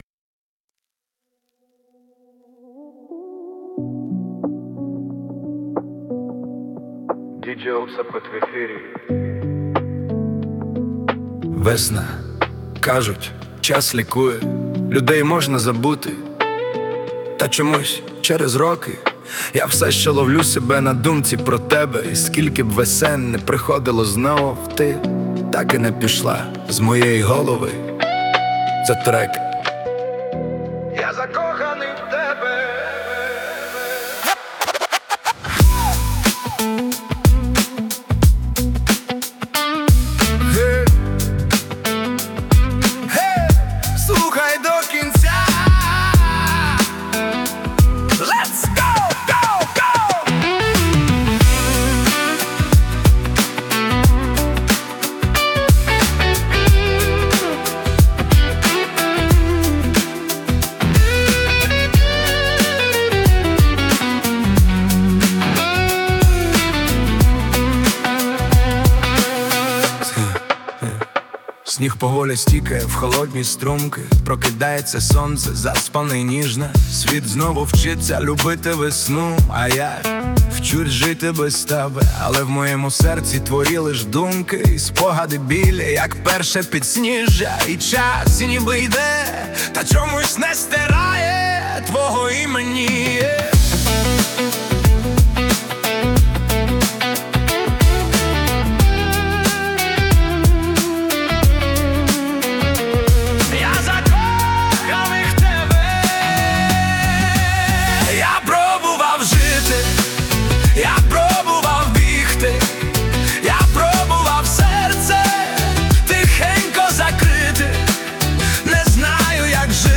Стиль: Гітарна балада